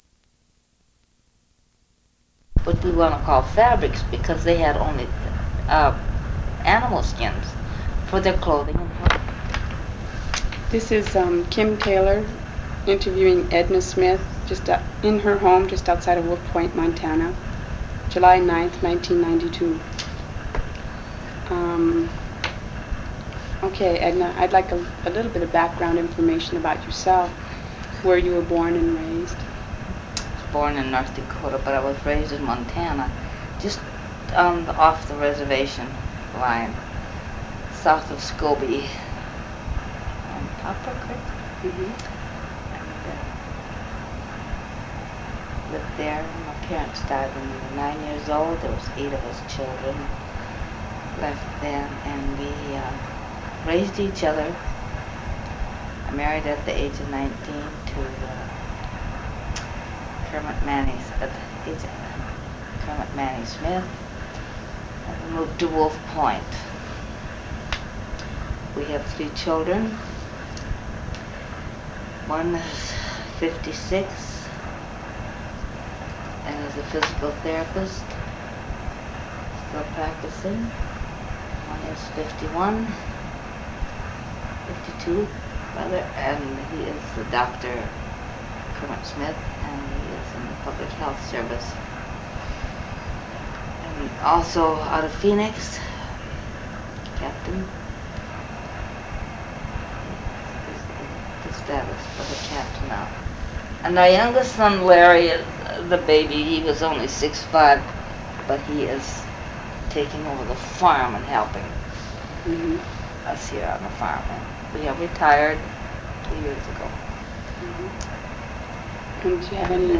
Oral History
1 sound cassette (01:03:05) analog